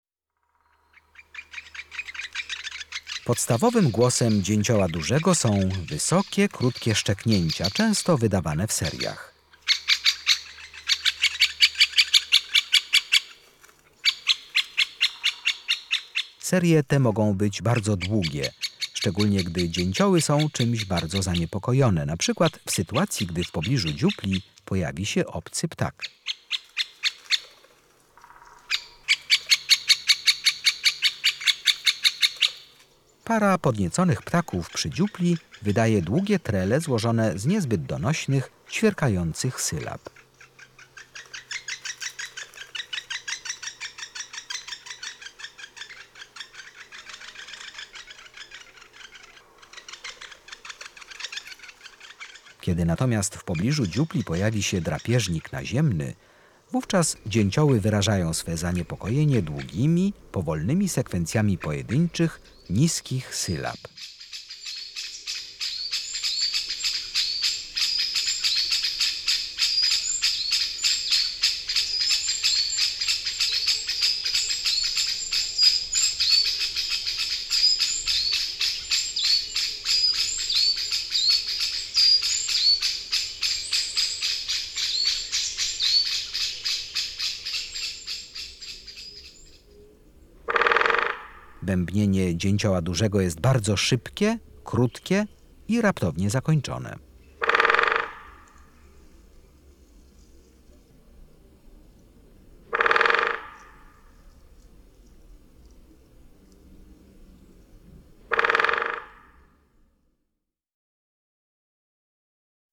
18-Dzieciol duzy.mp3